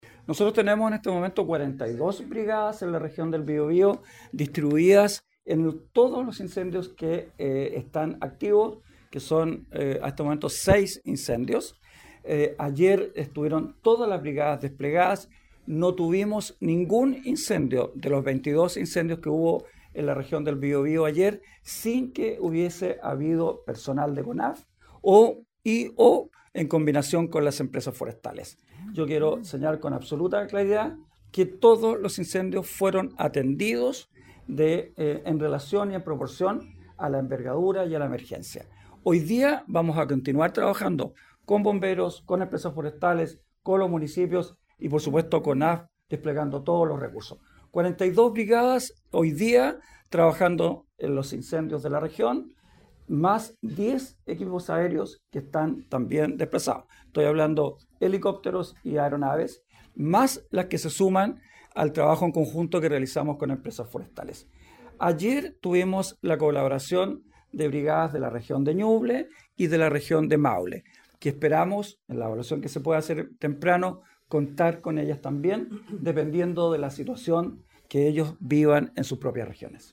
En total, 42 brigadas están desplegadas en el terreno, y seguirán operando hasta ahora en el combate de estos incendios, como aseguró Krause.